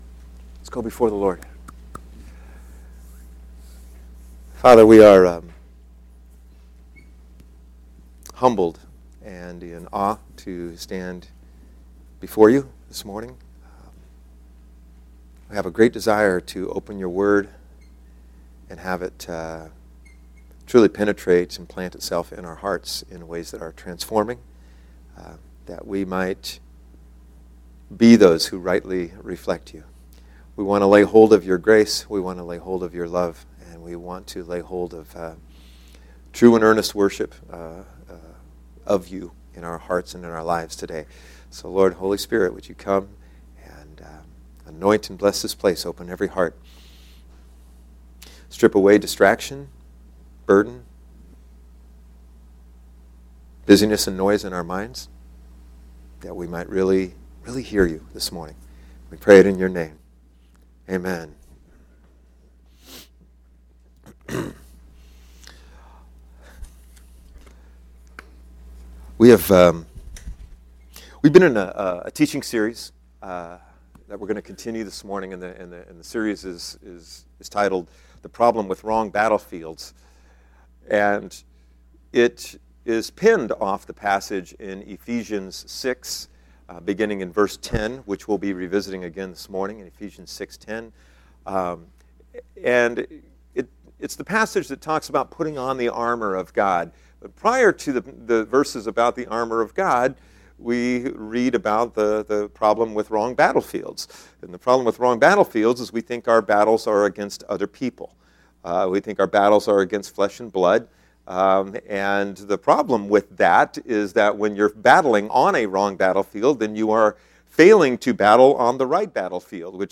Sermons - Lighthouse Covenant Church